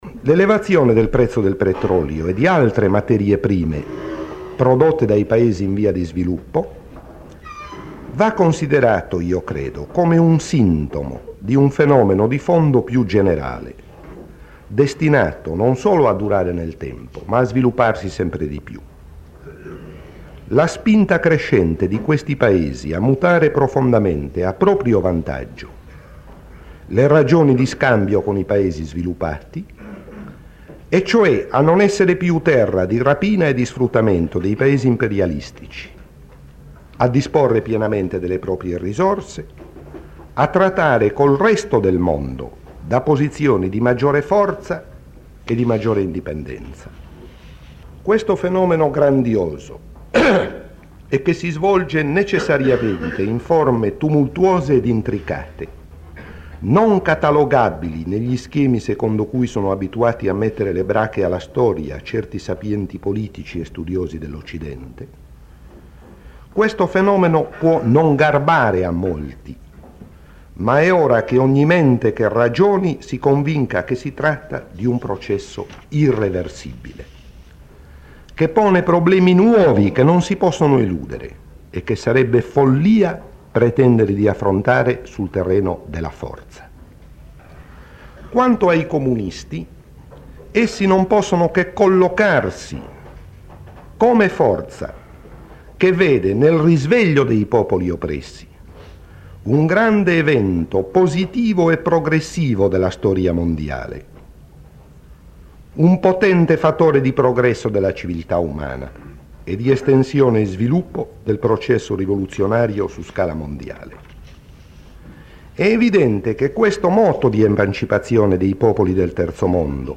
Sulla crisi energetica – Estratto dall’intervento al Comitato centrale del Pci  Roma 18 dicembre 1973